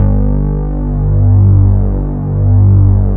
16 BASS   -R.wav